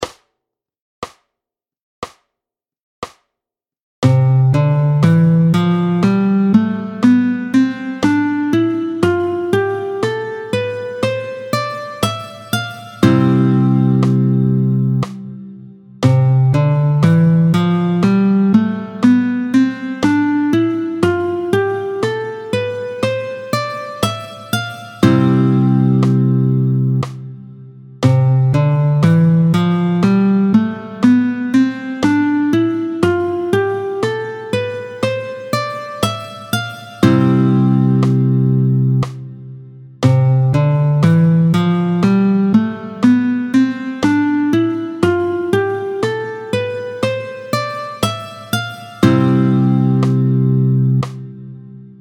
26-07 Doigté 1 Do majeur, tempo 60